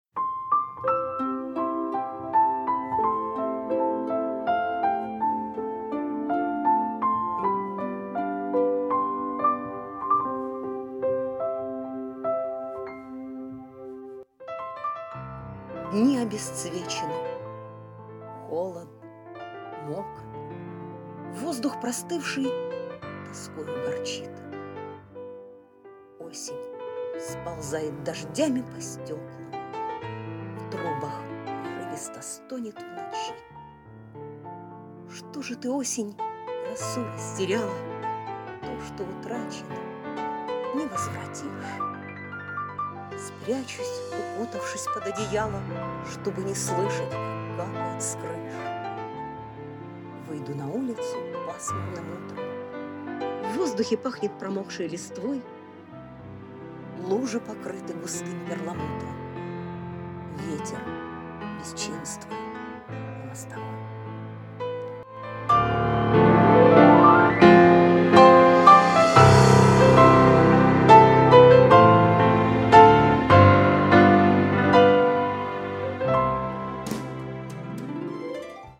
Это я что-то слишком тихо начитала, с непривычки.